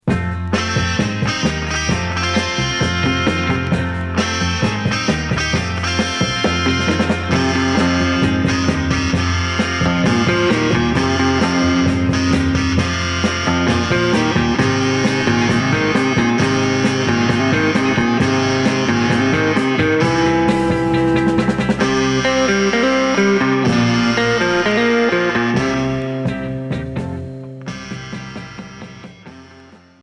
Pop jerk